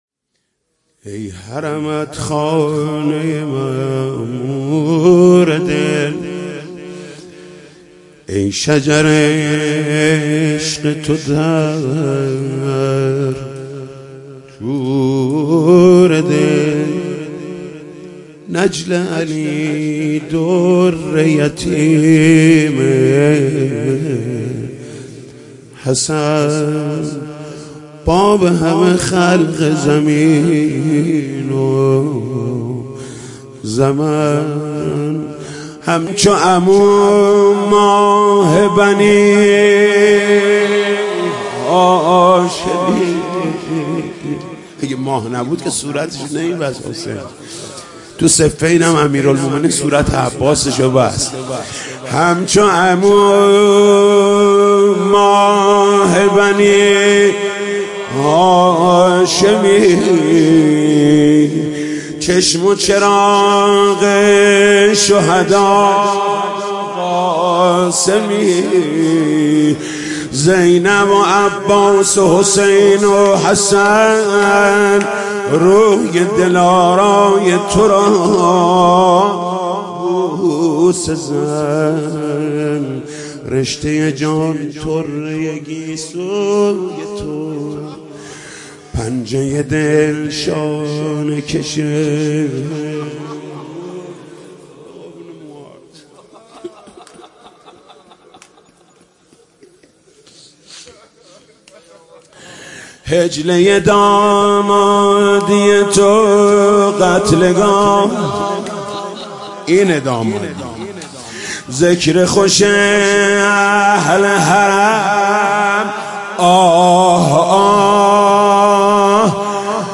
مداحی جديد محمود کريمی, نوحه جديد محمود کريمی